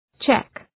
Προφορά
{tʃek}